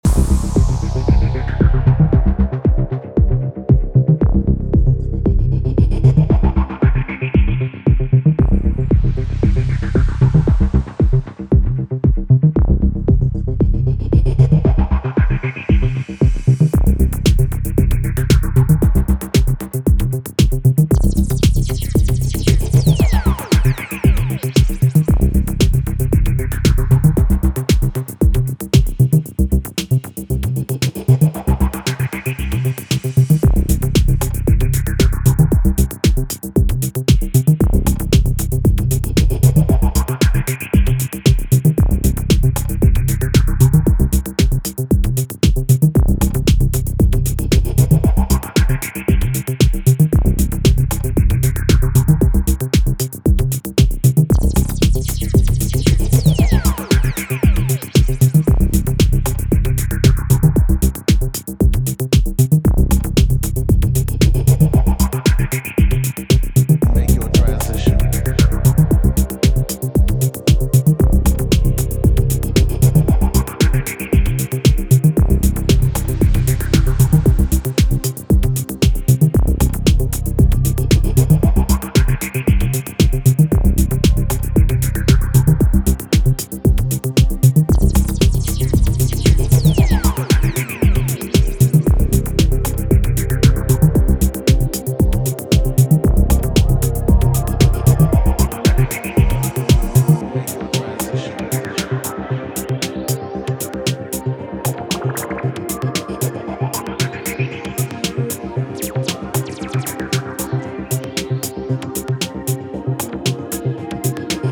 hypnotic tech house aesthetic